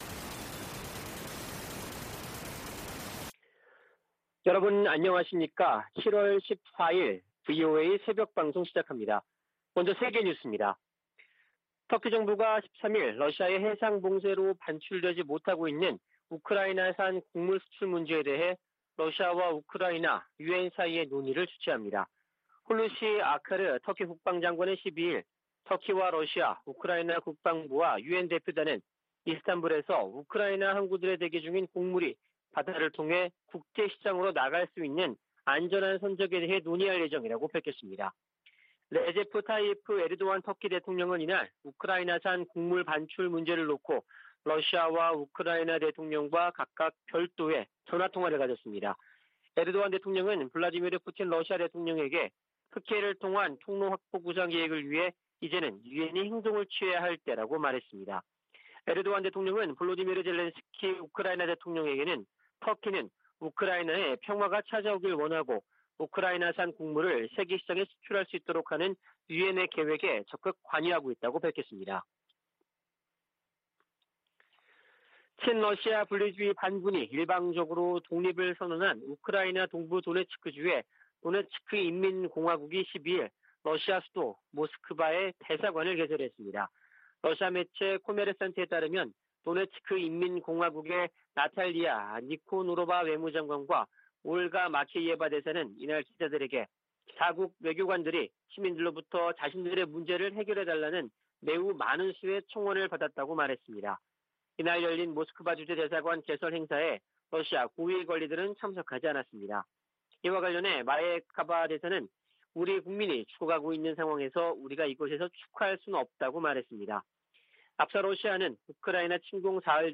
VOA 한국어 '출발 뉴스 쇼', 2022년 7월 14일 방송입니다. 전임 도널드 트럼프 미국 행정정부에서 고위 관료를 지낸 인사들이 강력한 대북 제재로, 김정은 국무위원장이 비핵화의 길로 나오도록 압박해야 한다고 말했습니다. 미 국무부 선임고문이 한국 고위 당국자들을 만나 양국 관계 강화와 국제 현안 협력 방안을 논의했습니다. 북한이 최근 방사포를 발사한 것과 관련해 주한미군은 강력한 미한 연합방위태세를 유지하고 있다고 밝혔습니다.